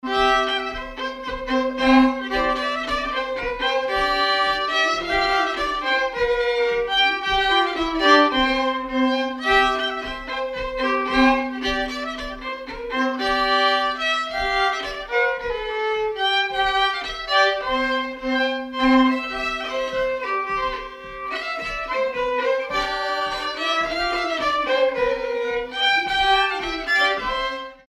danse : ronde
circonstance : bal, dancerie
Pièce musicale inédite